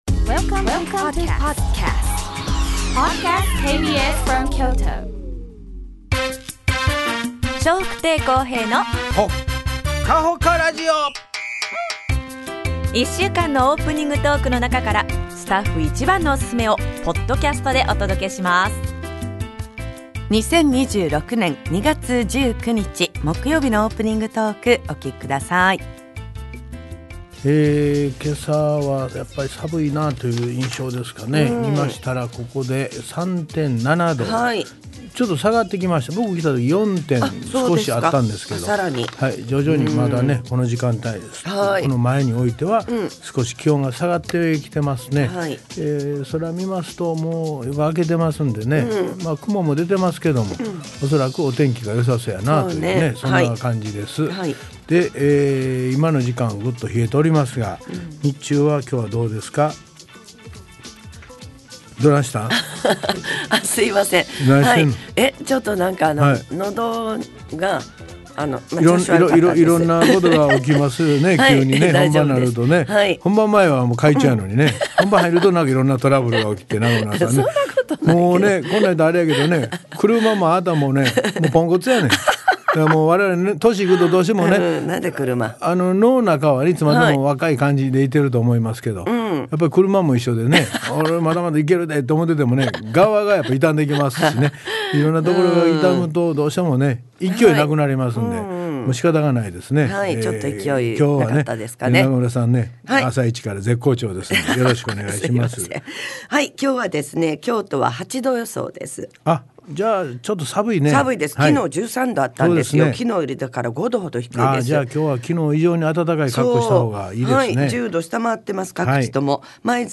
2026年2月19日のオープニングトーク
それではお二人のやりとりをお楽しみください♪